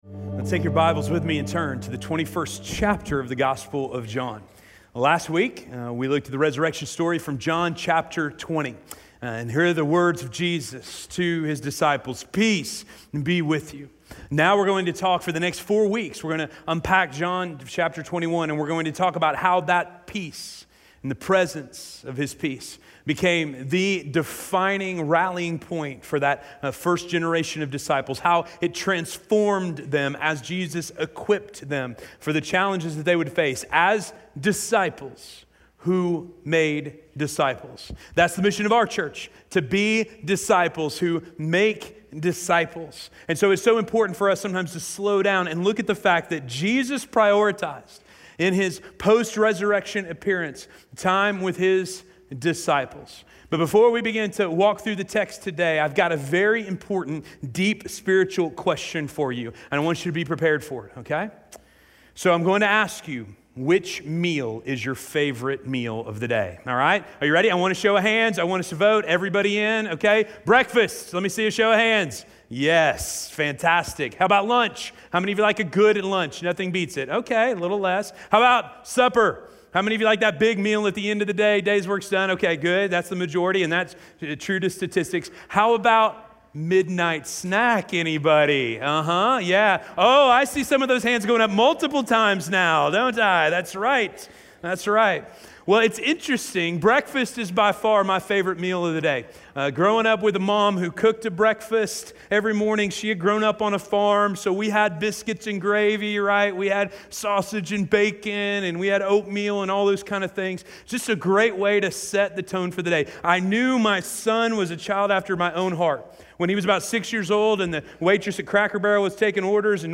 Breakfast with Jesus - Sermon - Station Hill